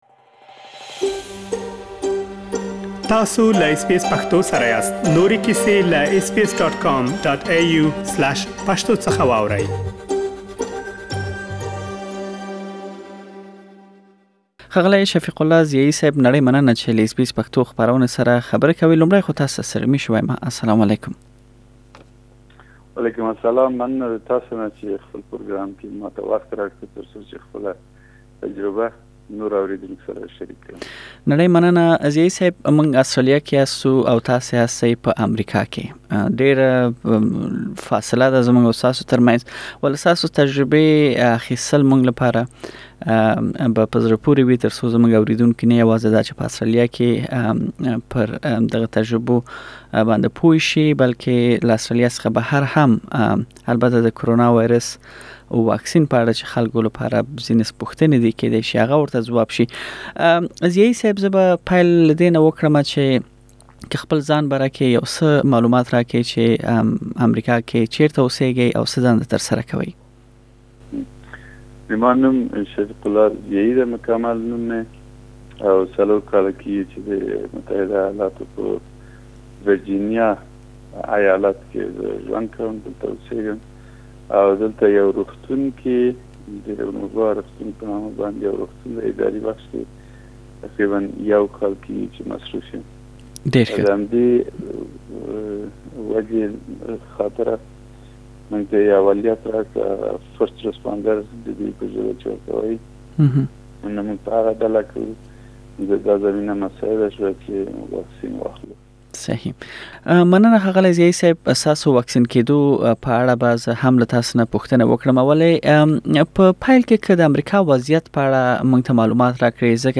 ددي پوښتنو ځواب مرکه کې موندلی شئ.